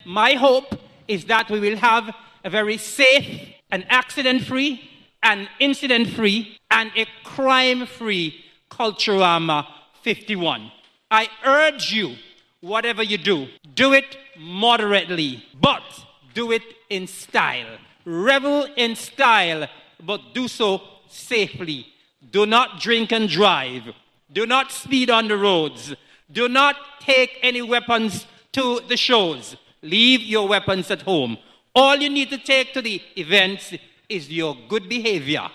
Further, the Culture Minister had this message for the public: